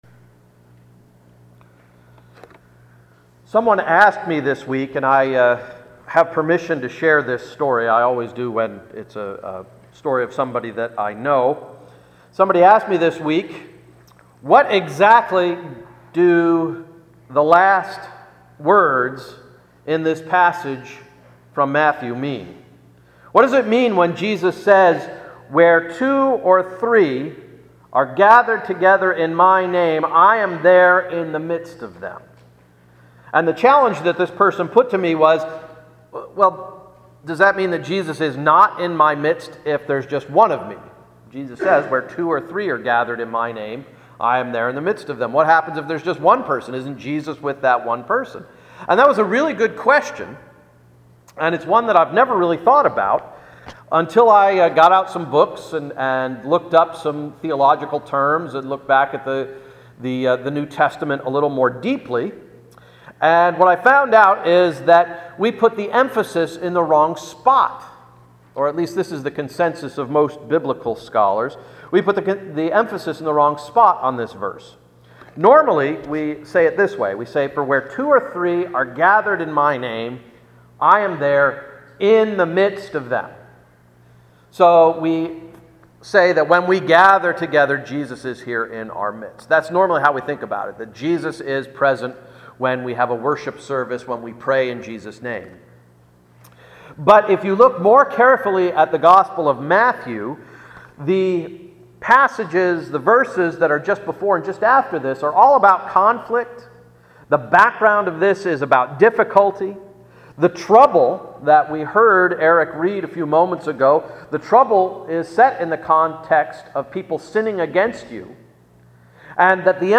Sermon of September 7, 2014–“Erasing Those Mistakes” – Emmanuel Reformed Church of the United Church of Christ